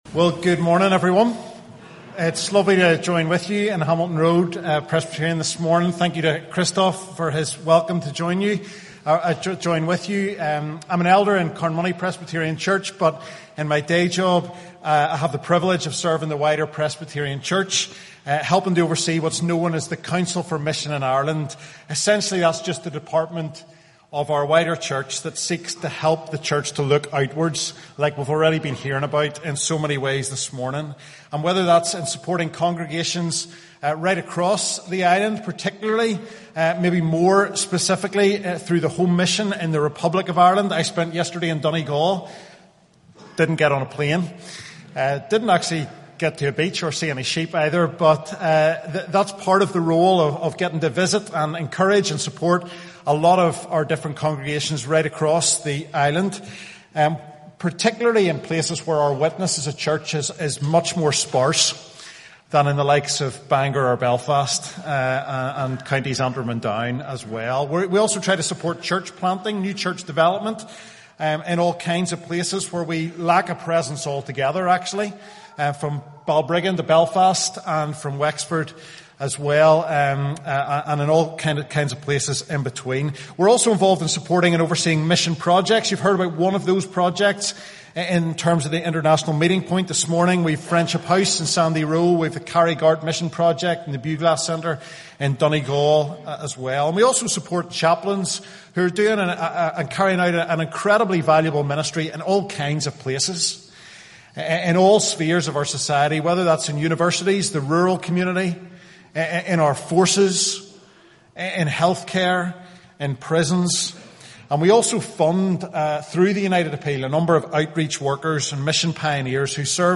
Summary: The sermon highlights how God’s mission invites us to join in His work across Ireland and beyond. Drawing from the early church’s experiences in Acts, we see that mission often leads us to unexpected places and people, reminding us that God is already at work in their lives.